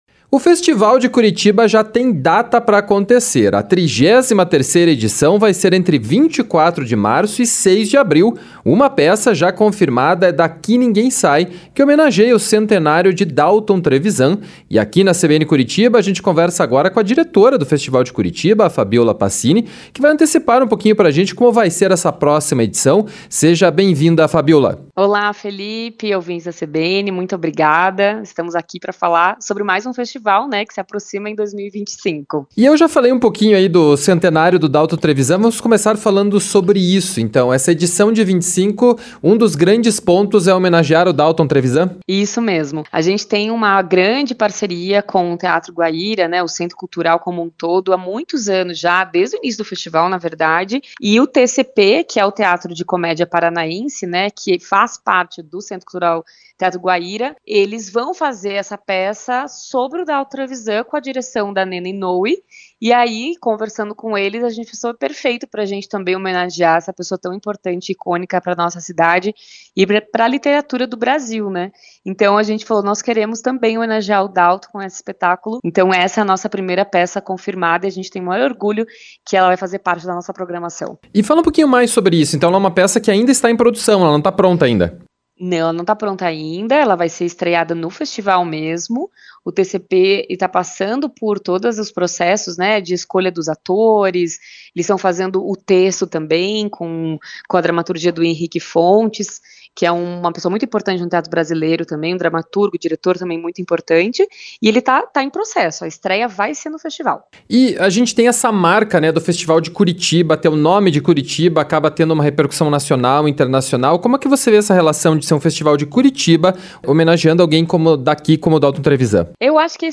ENTREVISTA-FESTIVAL-DE-CURITIBA-2025.mp3